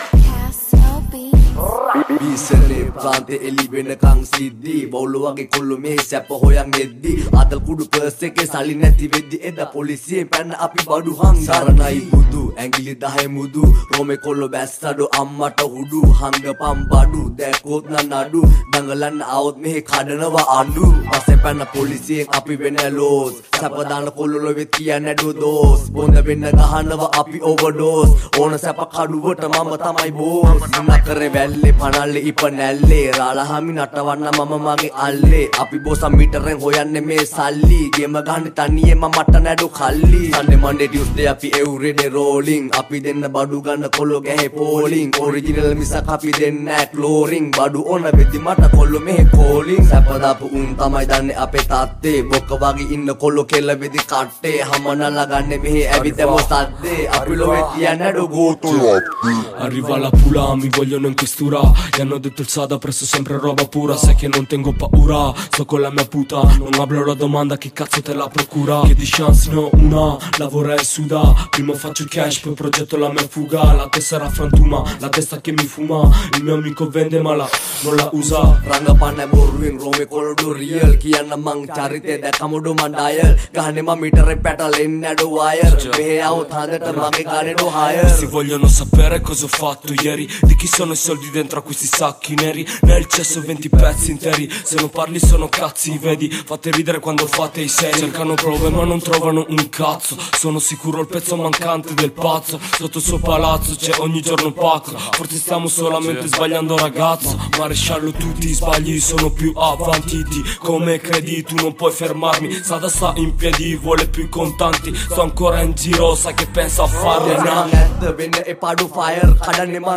sinhalarap rap